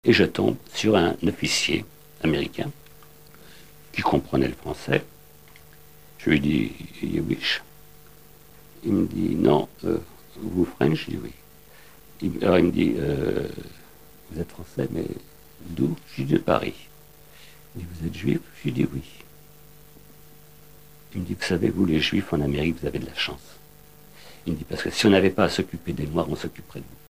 Témoignages de survivants.